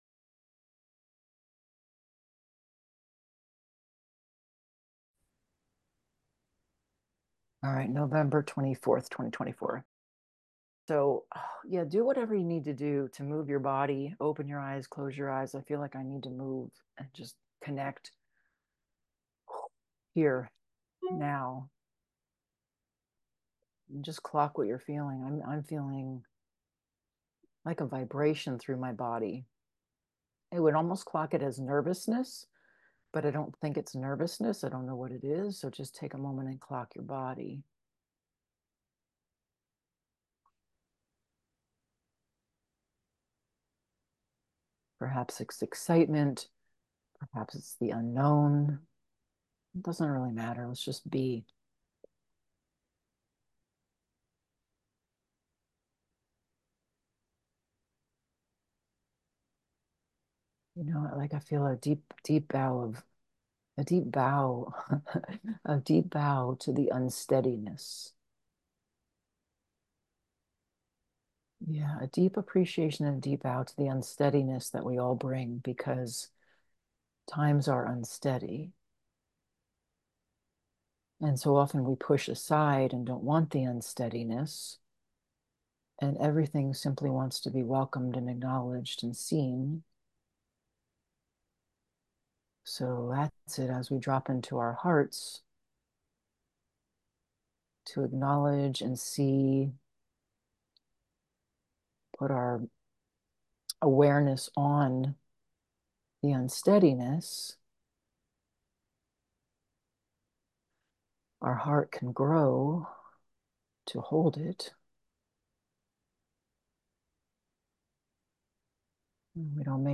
Guided SoulWork Meditation: From Unsteady to Free
A guided journey through the unsteadiness we are all living right now to the embodied freedom that is at the core. Note: This Guided SoulWork Meditation is from a small group experience held in November 2024.